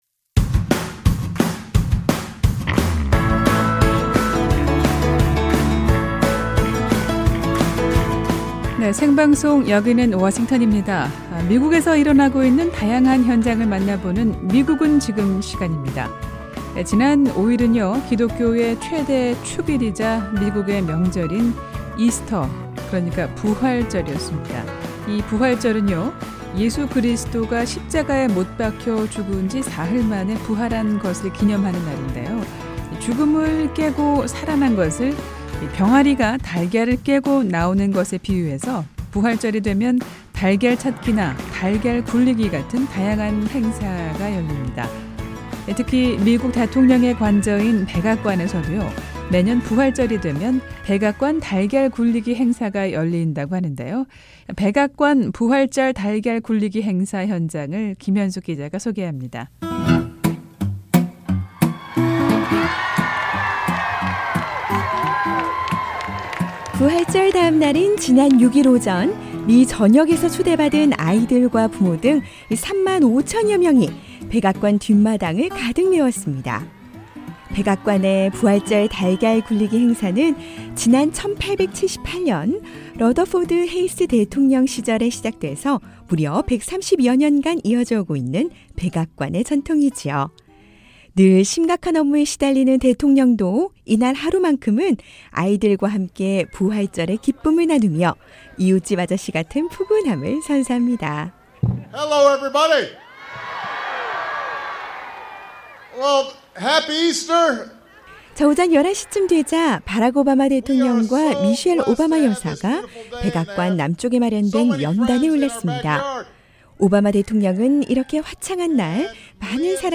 부활절 다음날인 월요일, 해마다 미국 백악관에서는 어린이와 부모를 초청해 하루를 즐기는 ‘부활절 달걀 굴리기’ 행사를 마련하는데요. 137회를 맞은 올해 행사 현장을 소개합니다.
‘부활절 달걀 찾기’ 행사장을 찾아 부활절을 즐기는 미국인들을 목소리를 들어봤습니다.